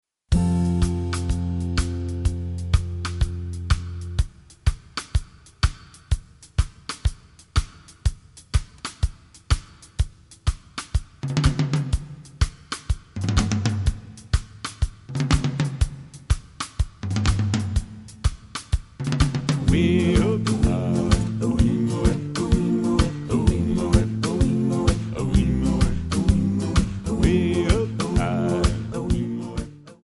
Backing track Karaoke
Pop, Oldies, 1960s